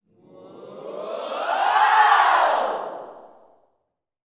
SWHOOPS 1.wav